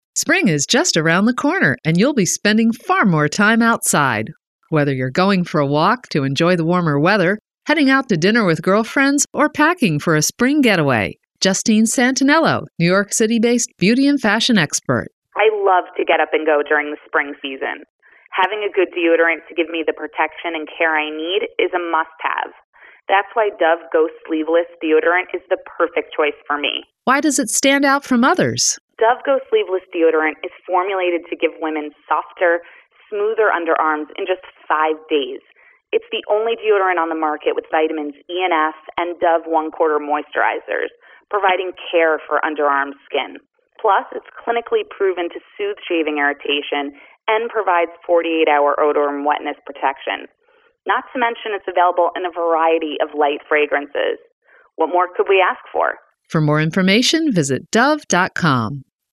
March 7, 2013Posted in: Audio News Release